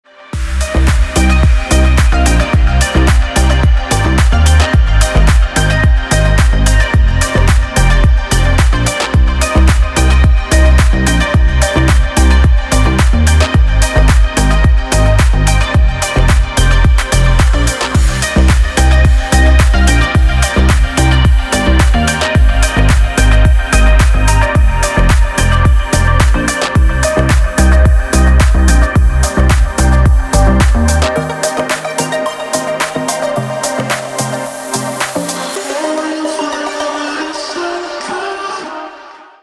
• Песня: Рингтон, нарезка
• Категория: Красивые мелодии и рингтоны